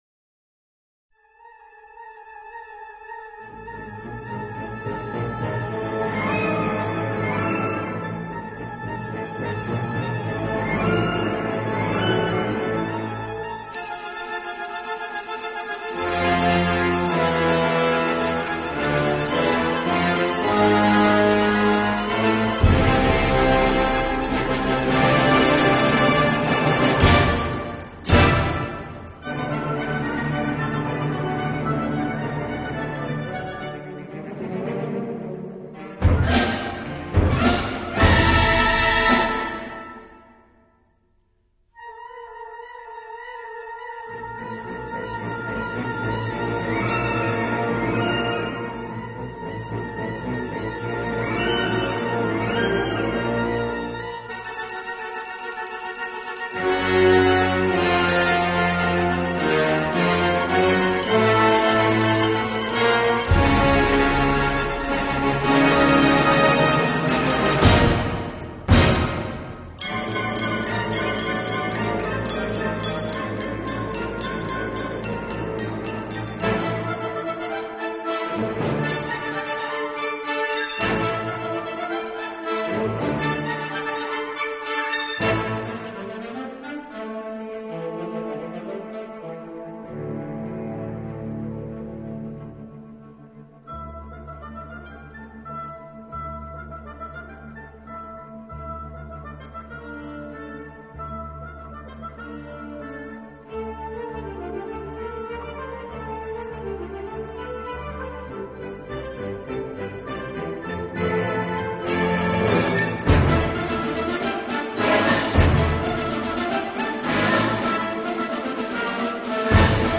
D minor（原調）